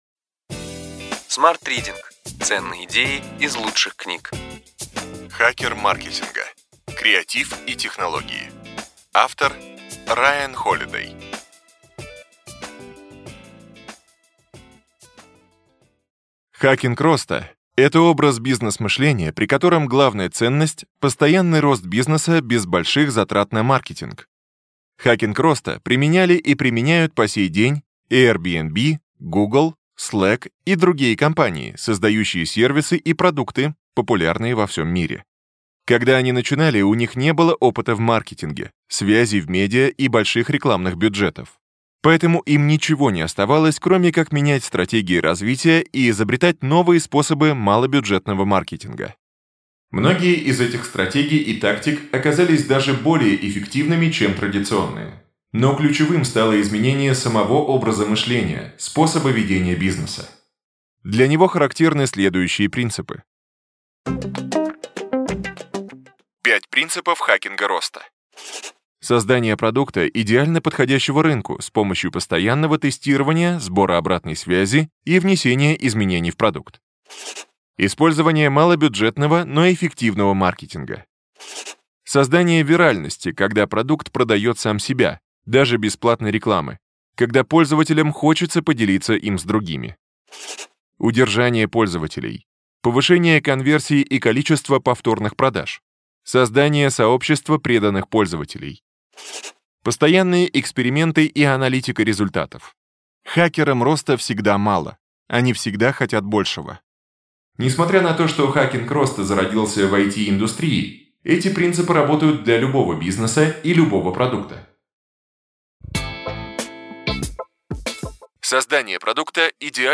Аудиокнига Ключевые идеи книги: Хакер маркетинга.
Прослушать и бесплатно скачать фрагмент аудиокниги